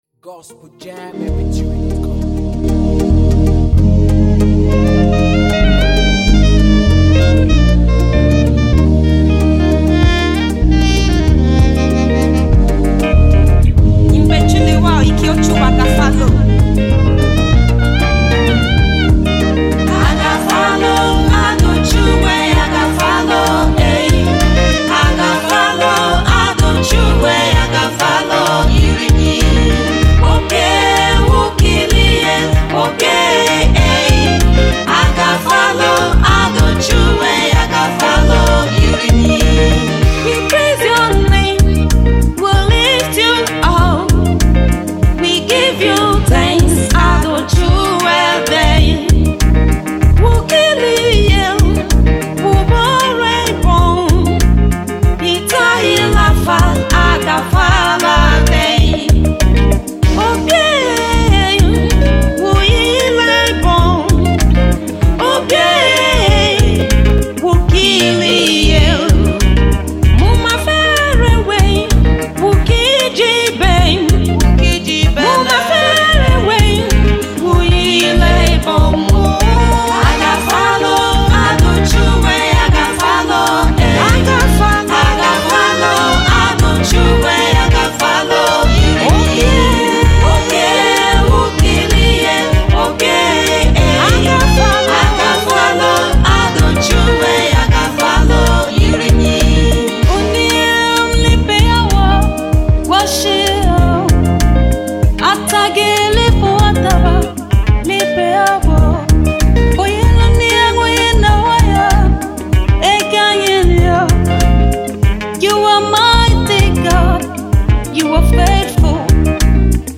A song of thanks giving and Appreciation to God.